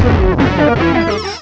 pokeemerald / sound / direct_sound_samples / cries / drifblim.aif